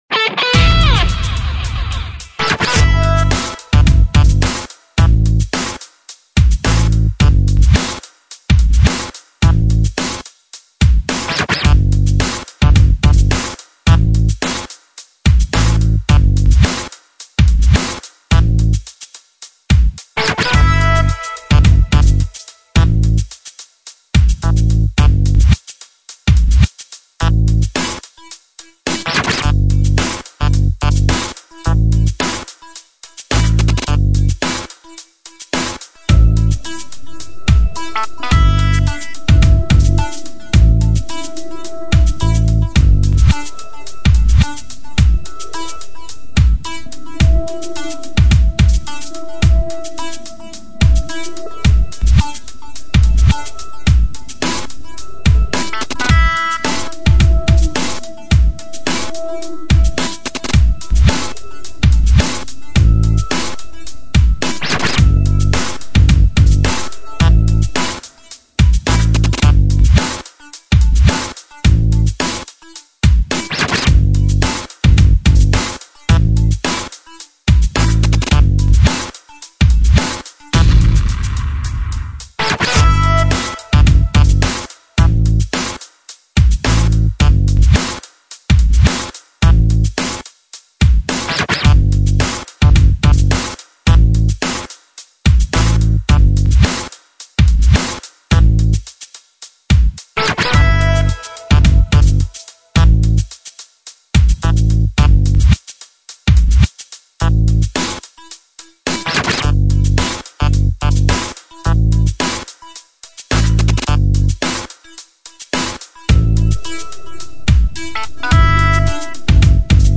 Leuk, maar niet 'warm' genoeg